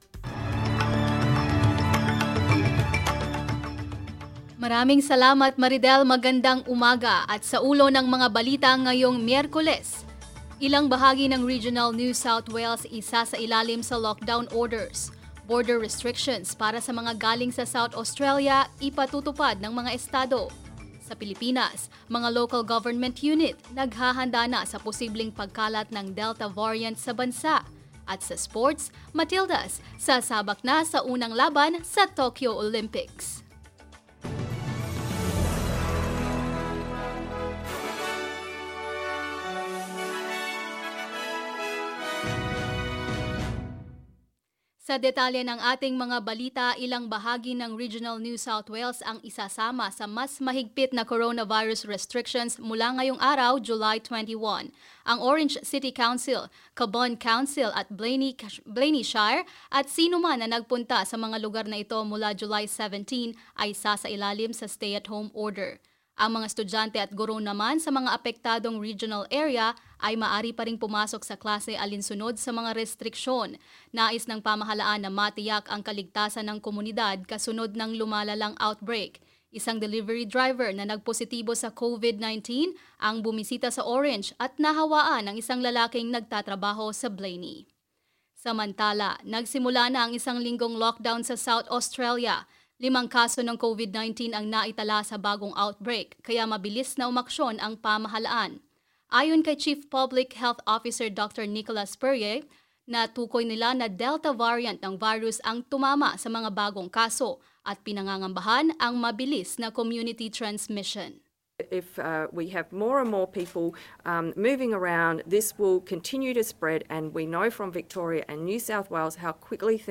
Mga balita ngayong ika-21 ng Hulyo